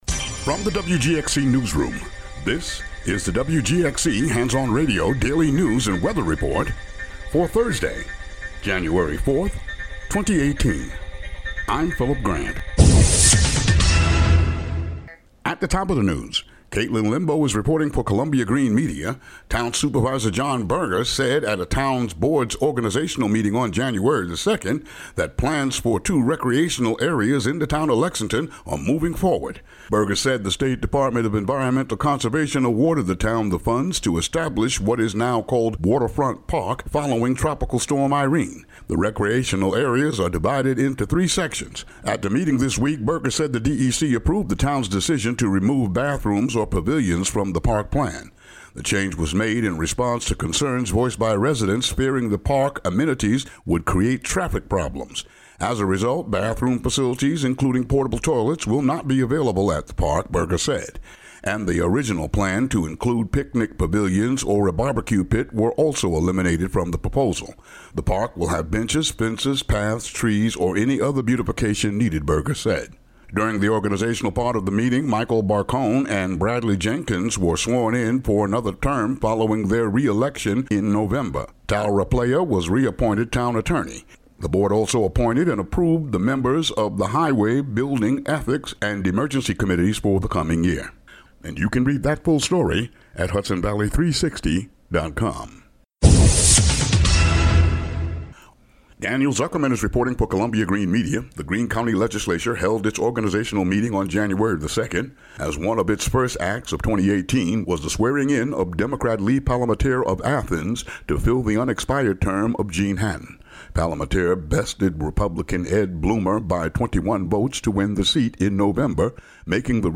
Daily local news for Thu., Jan. 4.